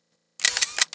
mach_parts2.wav